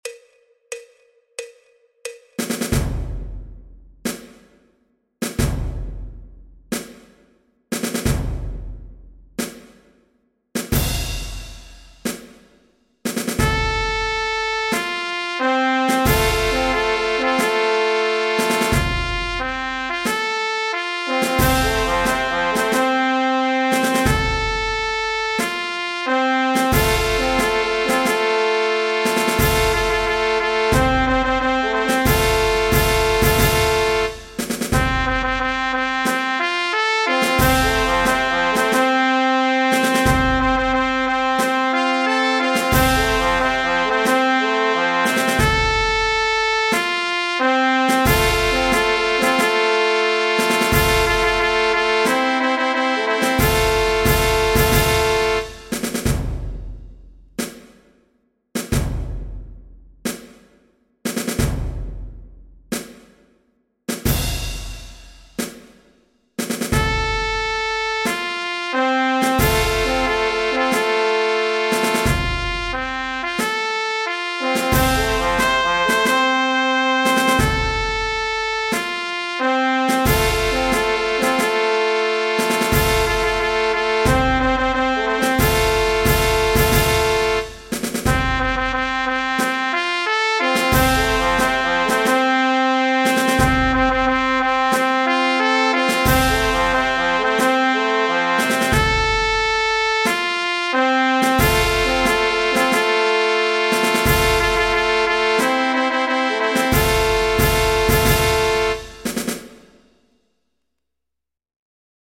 Marchas de Procesión
El MIDI tiene la base instrumental de acompañamiento.
Música clásica